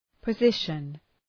Προφορά
{pə’zıʃən}
position.mp3